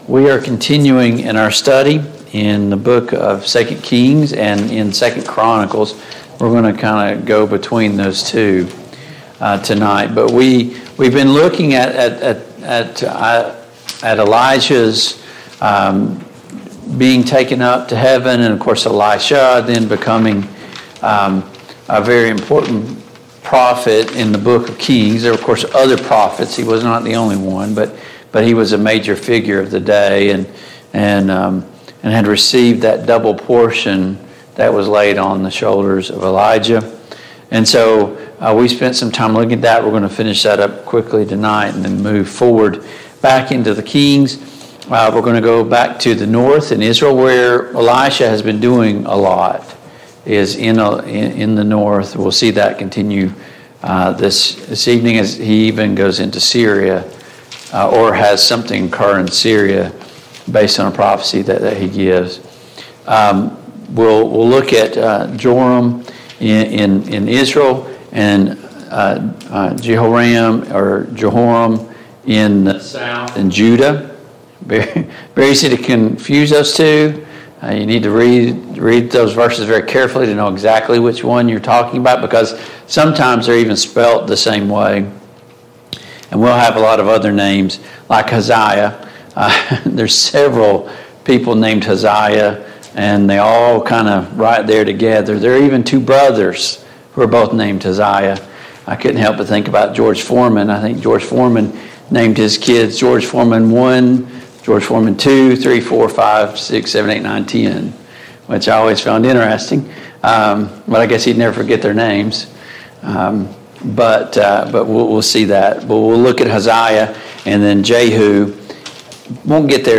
The Kings of Israel Passage: 2 Kings 8, 2 Kings 9, 2 Kings 10 Service Type: Mid-Week Bible Study Download Files Notes « 2.